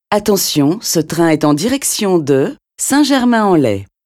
Cette voix que vous entendez, n’est pas une voix de synthèse parce qu’une voix robotique n’est pas très agréable à entendre… De ce fait, nous y sommes moins réceptifs.
Et un autre avec une voix humaine pour comparer :
Ex-voix-humaine.mp3